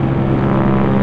1 channel
AmbDroneI.wav